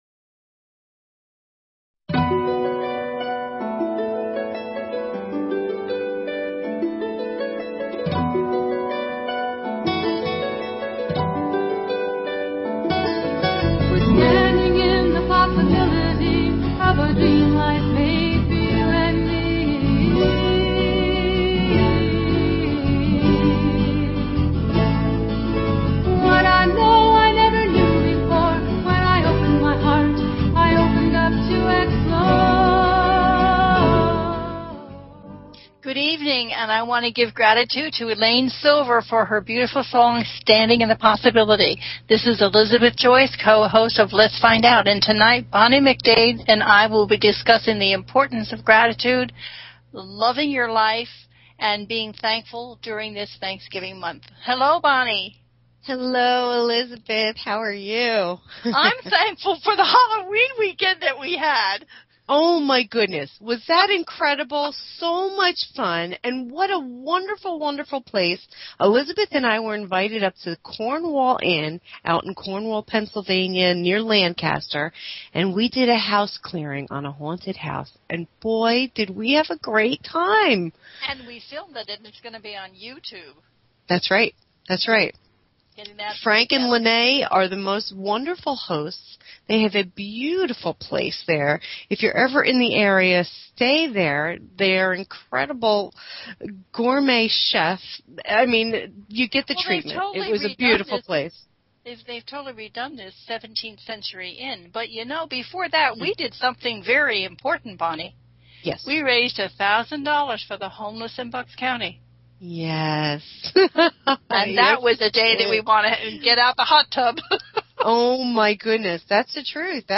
Talk Show Episode
The listener can call in to ask a question on the air.